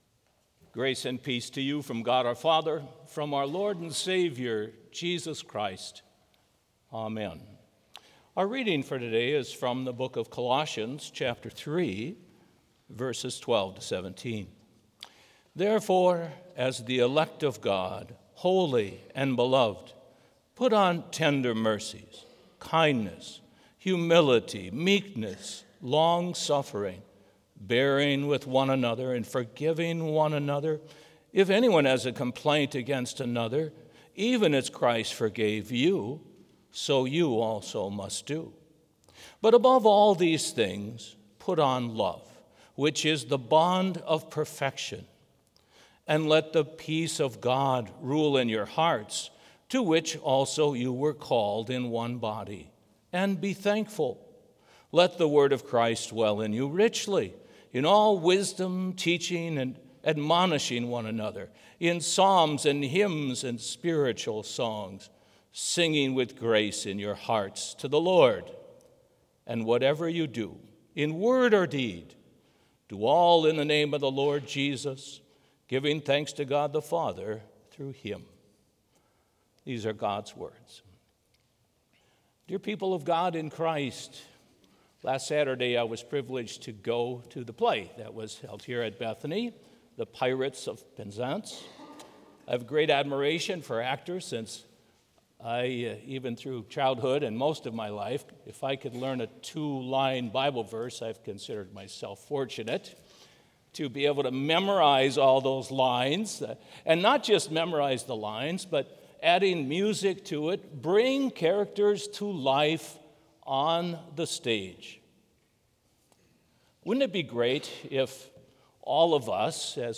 Complete service audio for Chapel - Thursday, February 13, 2025